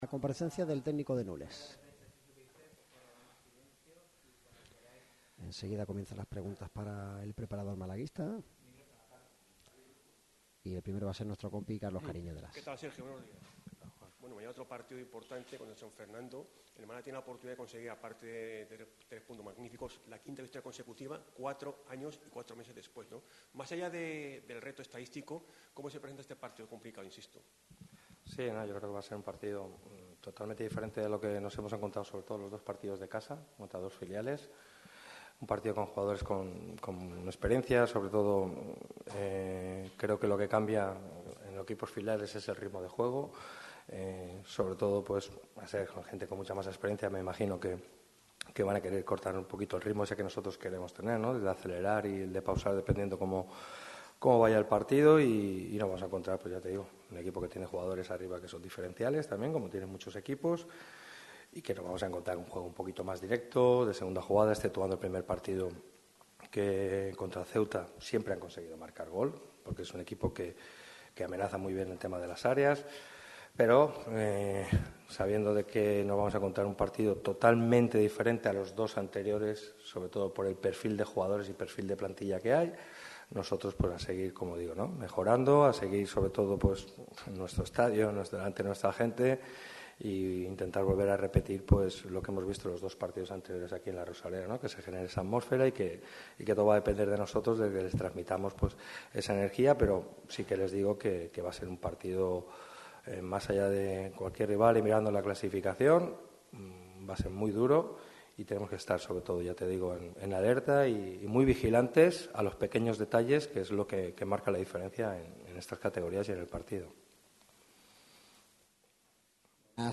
El entrenador malaguista ha comparecido antre los medios de comunicación en la sala de prensa de La Rosaleda con motivo de la previa del duelo de este domingo ante el San Fernando. El míster ha repasado determinados aspectos de la plantilla y habla sobre el rival.